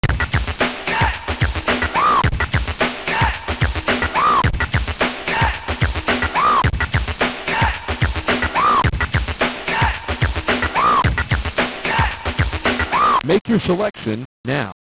digitized intro sound when you turn on the NES.